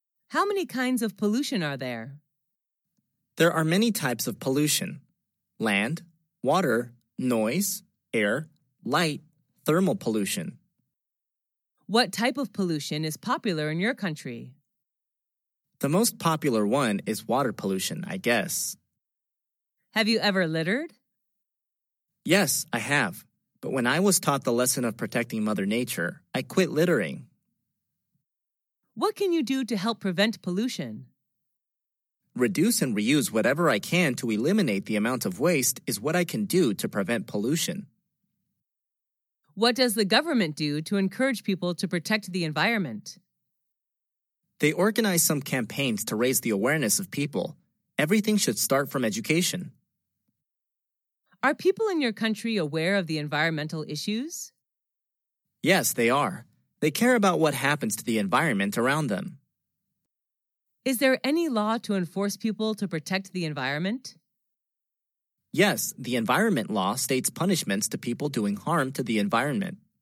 Sách nói | QA-39